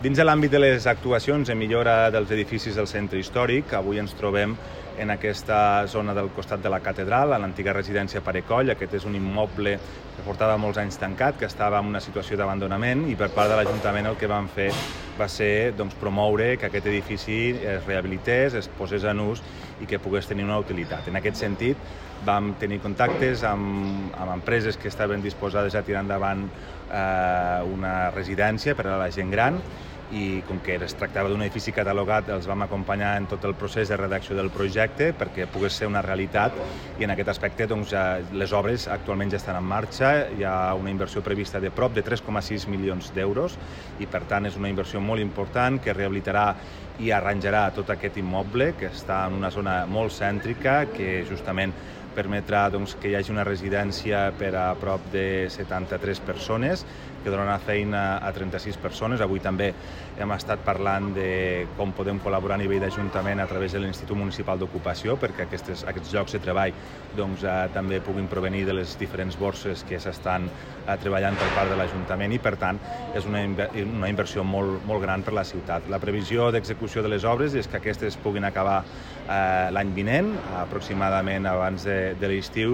tall-de-veu-de-lalcalde-accidental-toni-postius